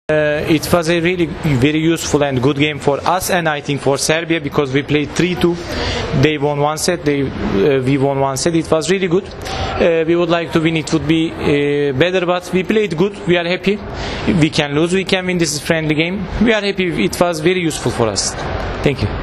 IZJAVA FERHATA AKBAŠA